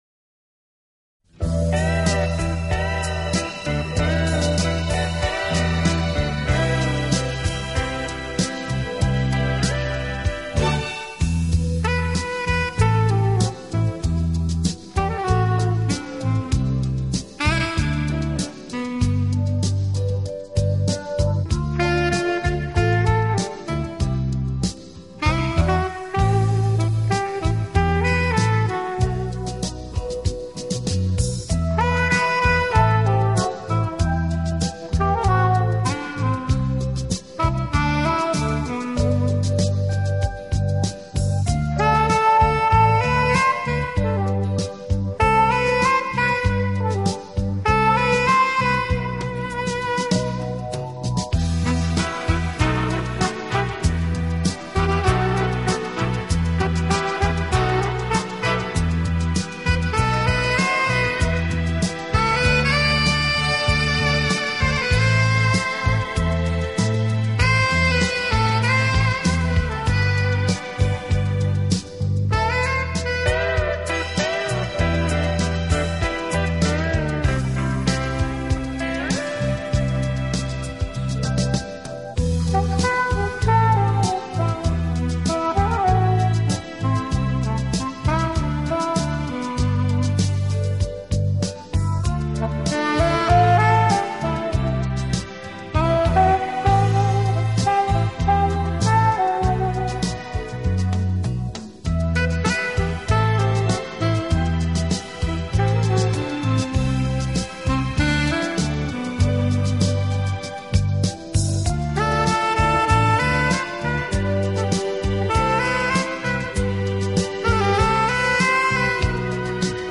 Genre....: Instrumental